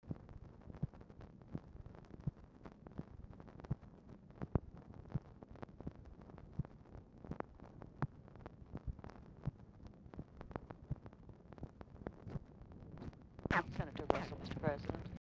Telephone conversation # 9801, sound recording, TELEPHONE OPERATOR, 3/1/1966, 9:20AM | Discover LBJ
Format Dictation belt
Location Of Speaker 1 Mansion, White House, Washington, DC
Speaker 2 TELEPHONE OPERATOR